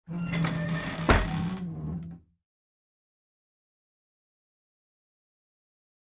Gate Open: Metal With Squeaks.